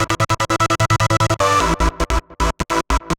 Index of /musicradar/future-rave-samples/150bpm
FR_JupeRaver_150-A.wav